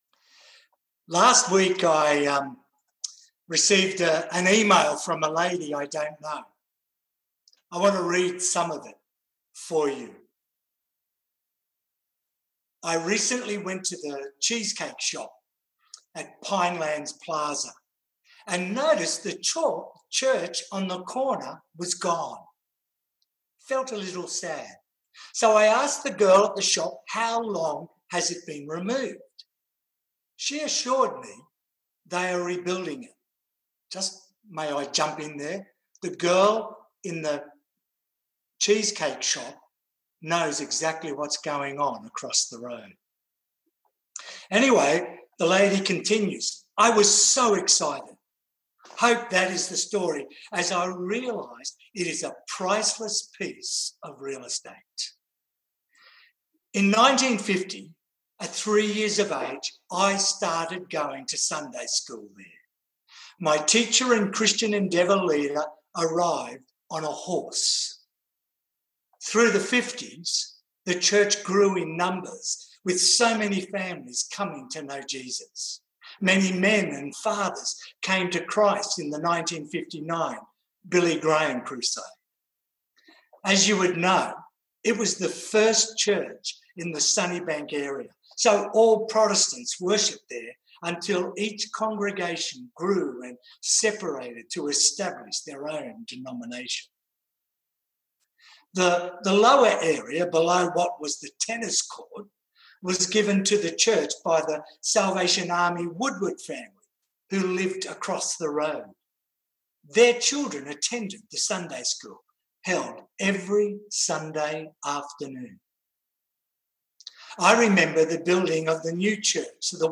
Categories Sermon Tags 2020